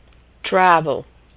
Lessons about authentic use and pronunciation of American English
Consonant Sound Voiced r = /ər/
r-travel.mp3